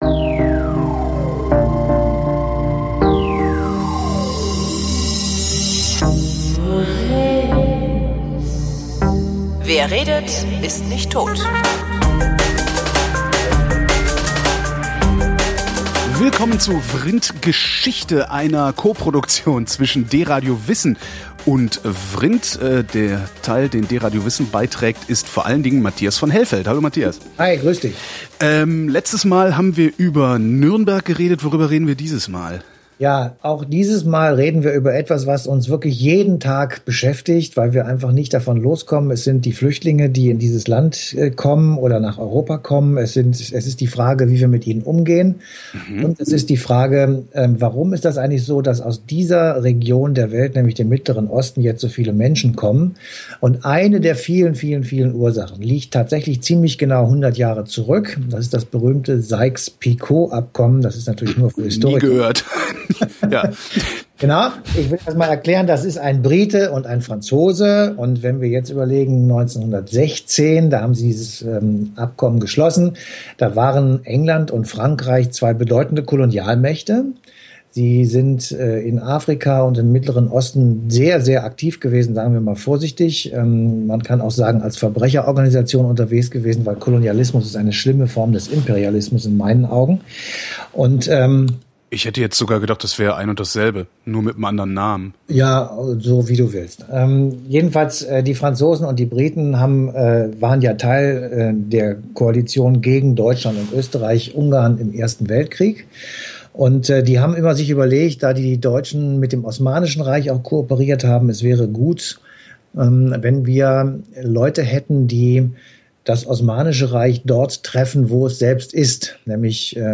(An der Klangqualität arbeiten wir noch. Ich bitte um Geduld)